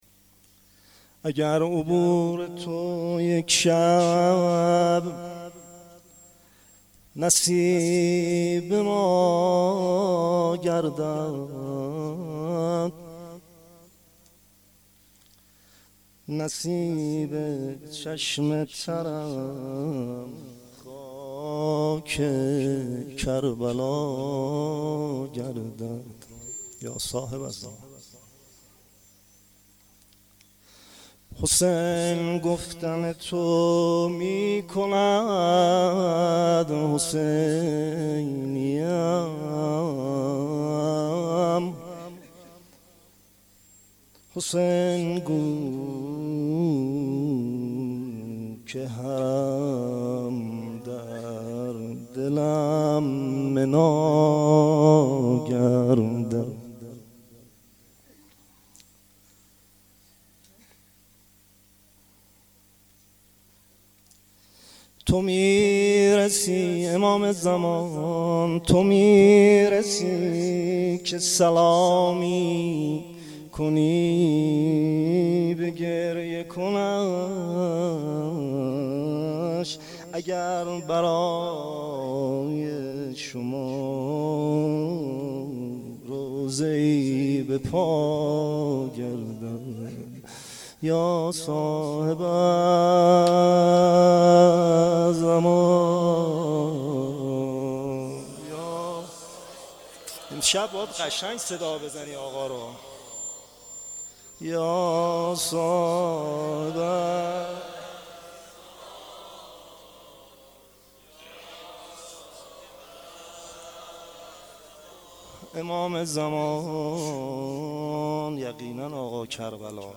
مناجات شب عاشورا محرم 93
Monajat-Shabe-10-moharram93.mp3